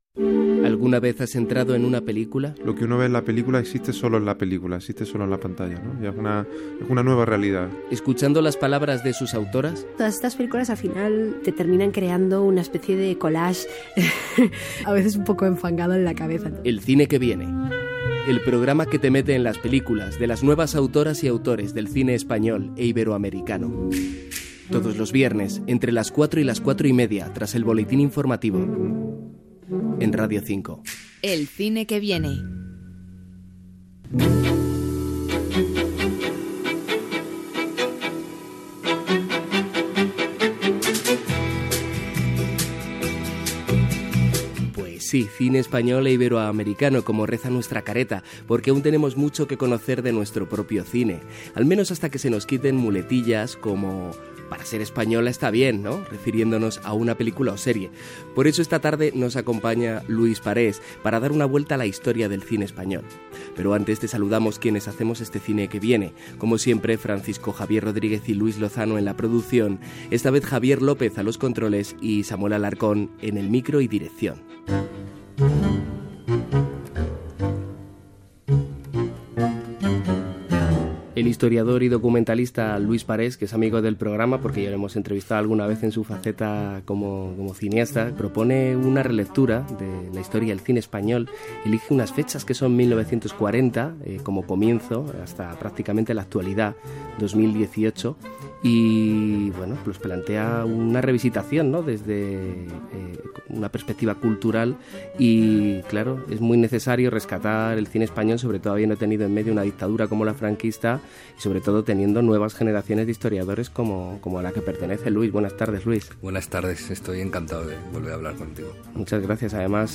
Careta, presentació i entrevista